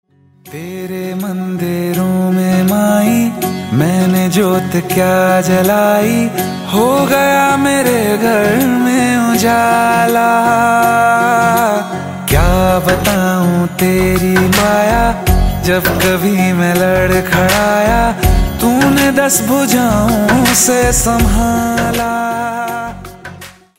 Hindi Songs